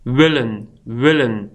willen.mp3